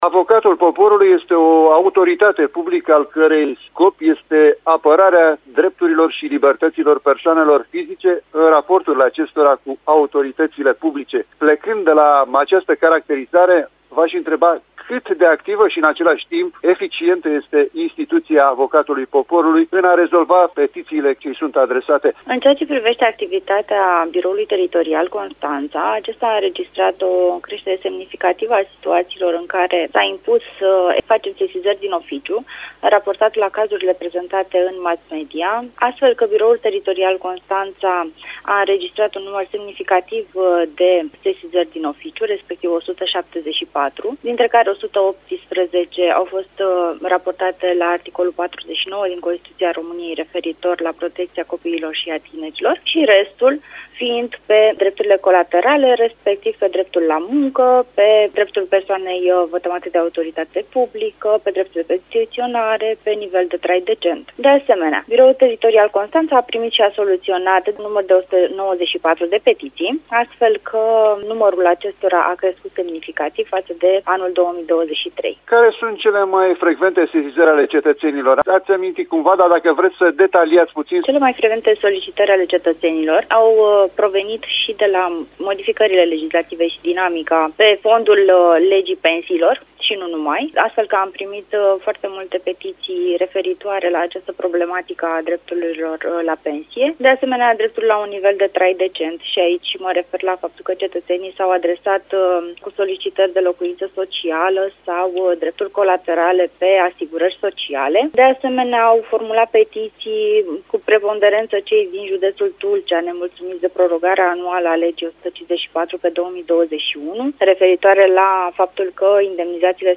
a discutat despre cazuistica și activitatea Avocatului Poporului